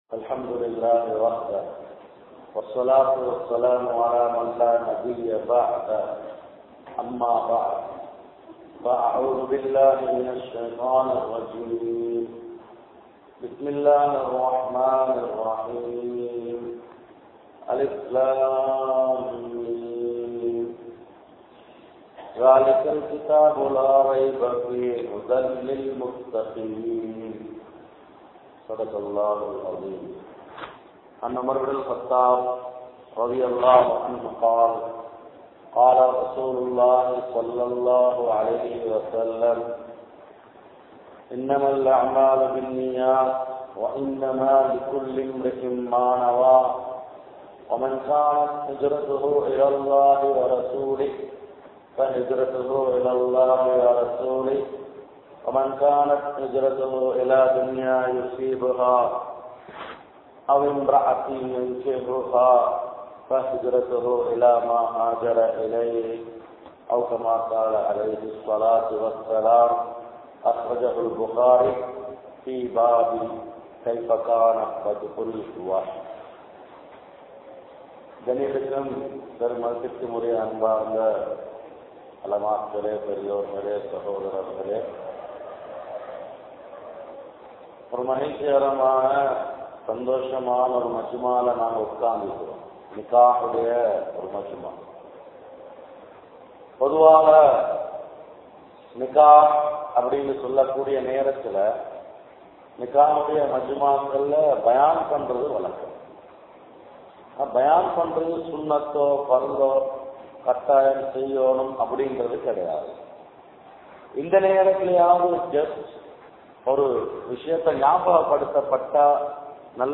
Indraya Muslimkalin Thirumanagal (இன்றைய முஸ்லிம்களின் திருமணங்கள்) | Audio Bayans | All Ceylon Muslim Youth Community | Addalaichenai
Thaqreen Jumua Masjidh